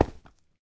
sounds / step / stone6.ogg
stone6.ogg